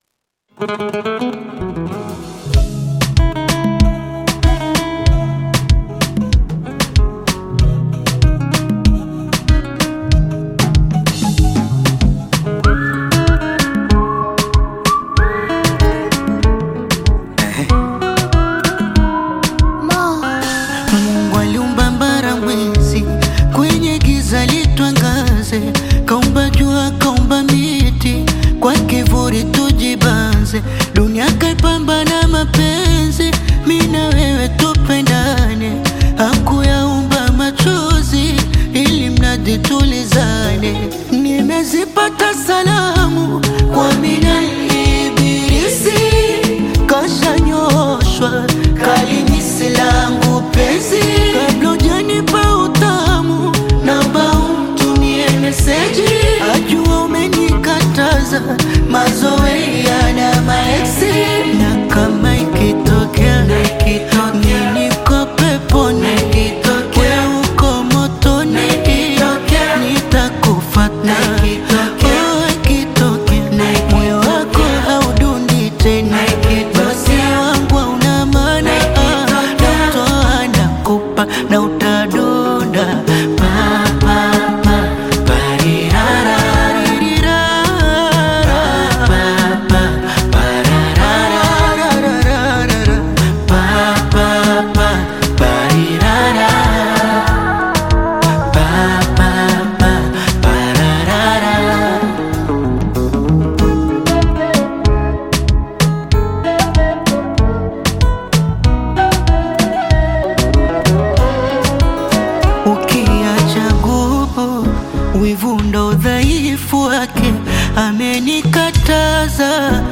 Bongo Flava
Tanzanian Bongo Flava artist, singer, and songwriter